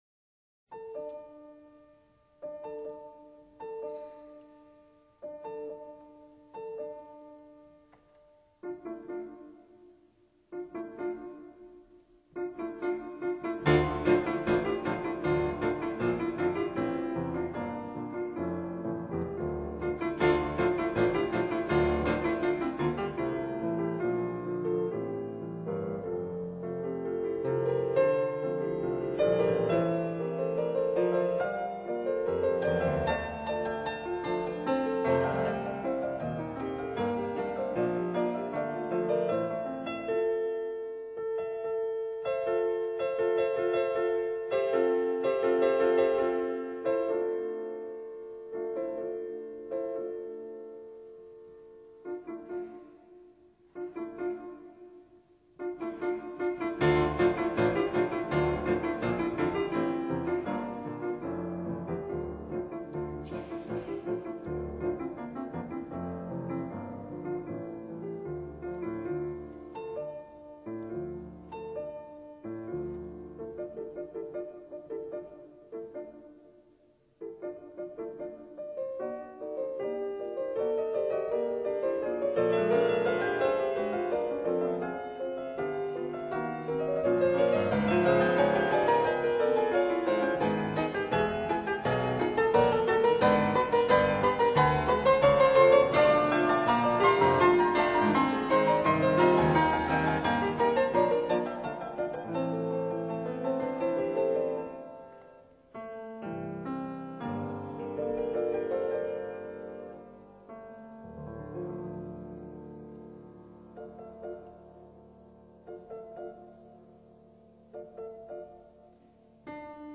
前苏联钢琴家。